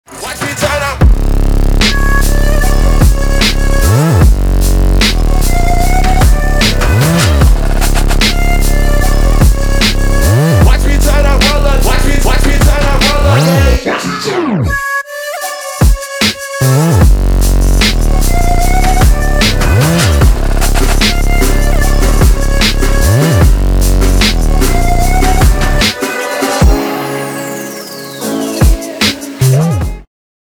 • Качество: 320, Stereo
Trap
Bass